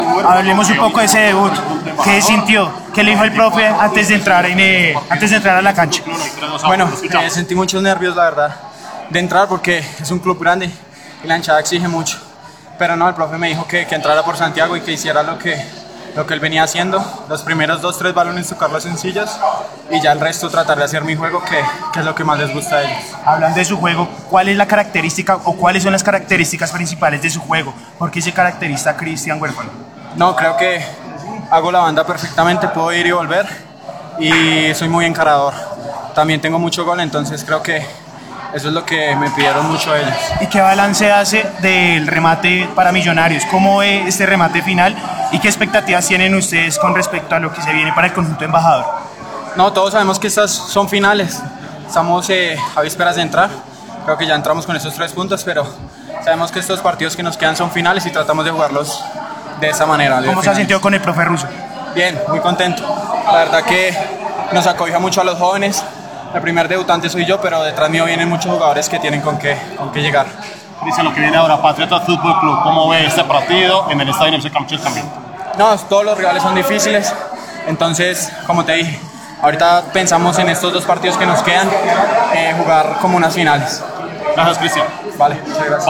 Esta fue la primera entrevista